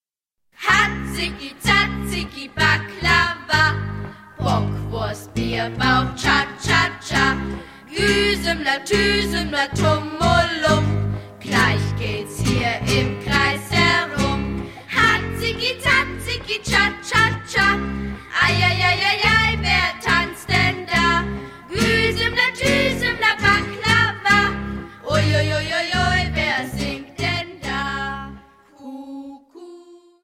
Ein Klangspektakel, das einfach Spaß macht!